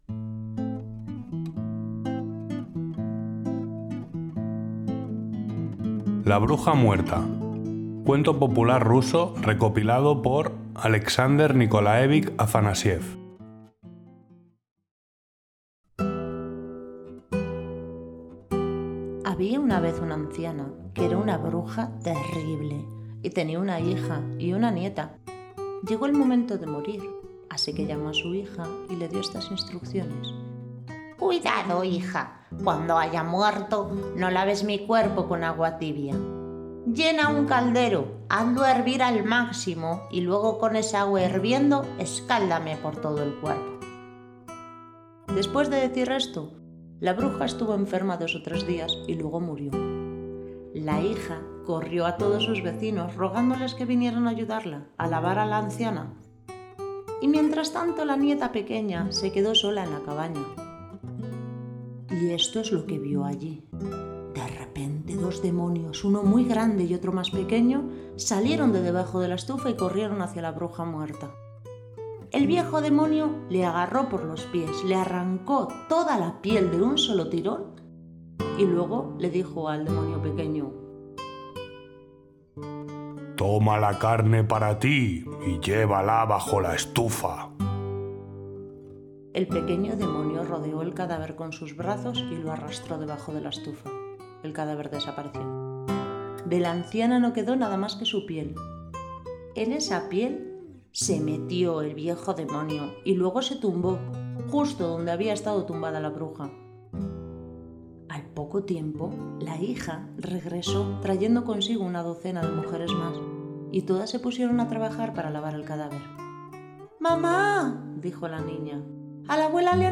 Cuento popular ruso recopilado por Aleksandr Nikolaevich Afanasiev (1826-1871) Narradores: